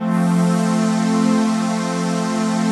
CHRDPAD018-LR.wav